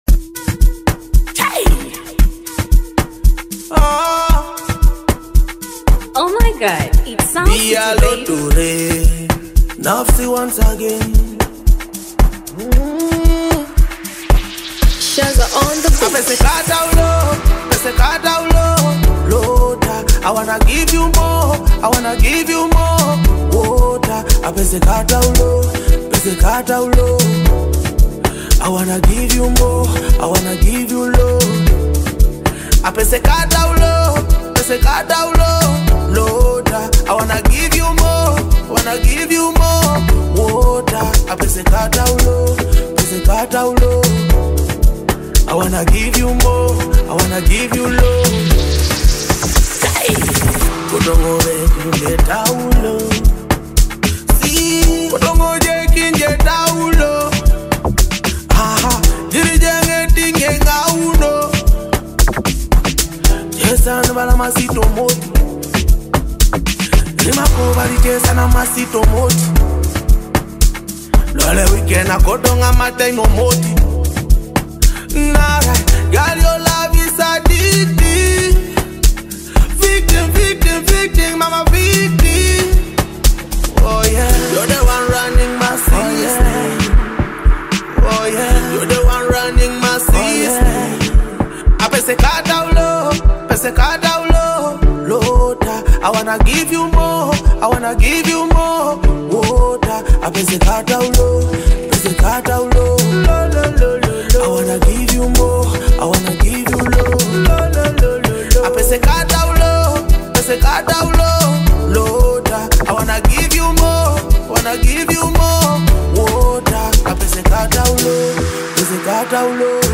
vibrant rhythm